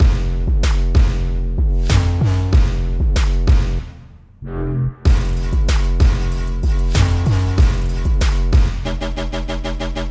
肮脏的陷阱大鼓
描述：808，拍子，Hihats，摇摆，铜管。
Tag: 95 bpm Trap Loops Drum Loops 1.70 MB wav Key : Unknown